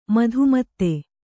♪ madhu matte